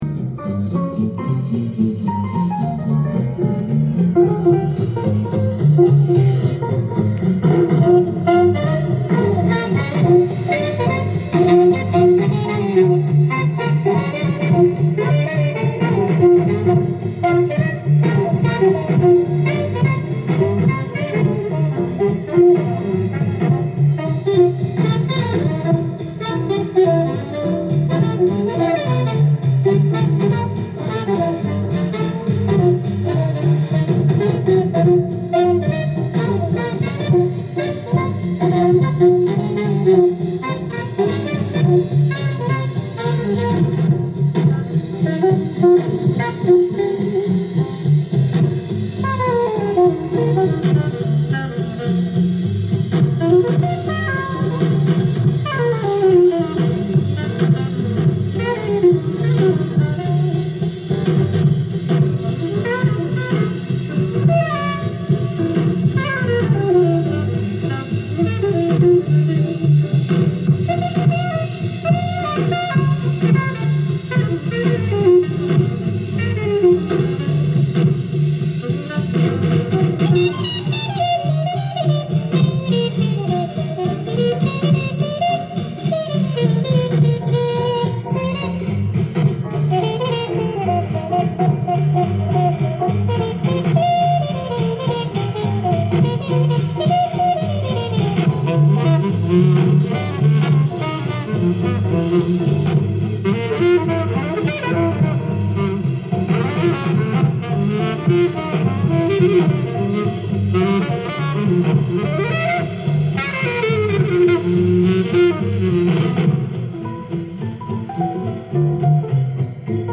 LP
店頭で録音した音源の為、多少の外部音や音質の悪さはございますが、サンプルとしてご視聴ください。
やや、ブルーな演奏から、典型的なバップ演奏まで、まさに集大成といえる、これぞモダン・ジャズといった内容！！